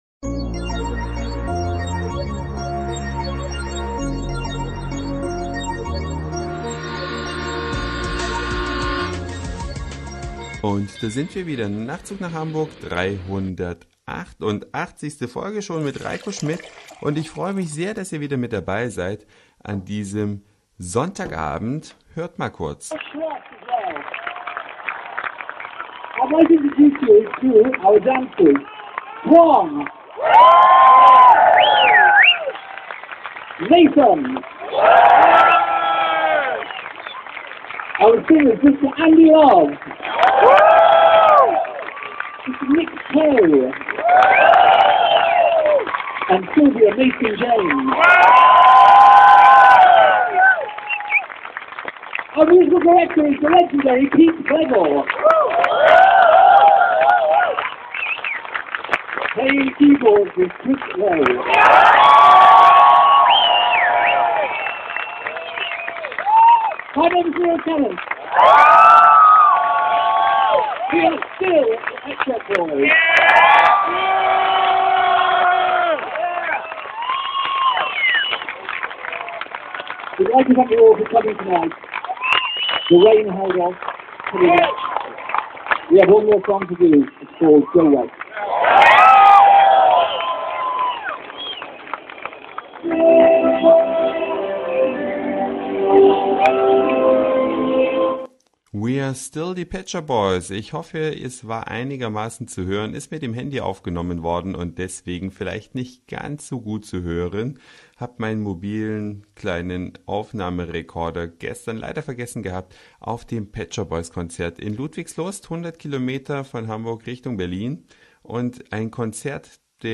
Eine Reise durch die Vielfalt aus Satire, Informationen, Soundseeing und Audioblog.
Eine Stunde vor Konzertbeginn